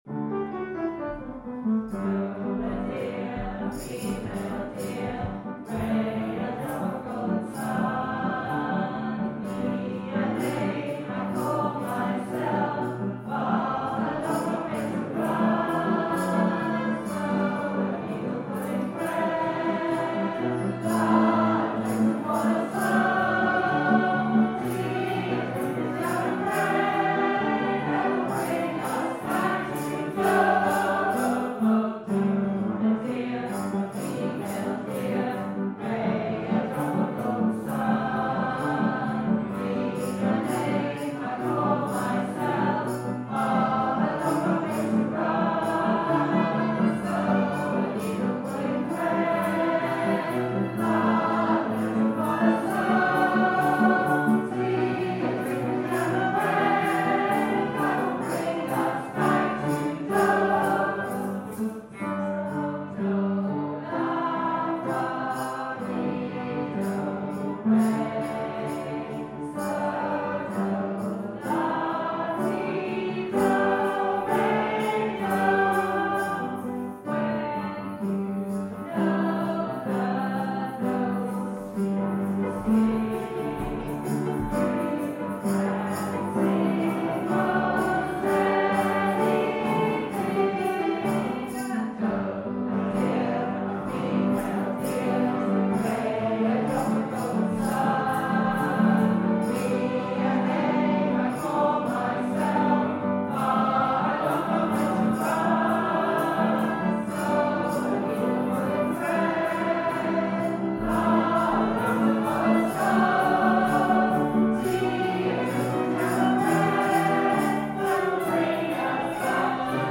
piano
This concert was a program of songs from the Sound of Music, ABBA and Irish folksongs. The choir, made up of blind and partially sighted singers
viola
sitar and drums, with other percussion by audience members.